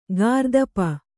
♪ gārdapa